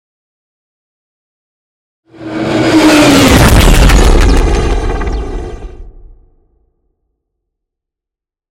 Scifi passby whoosh
Sound Effects
Atonal
futuristic
high tech
intense
pass by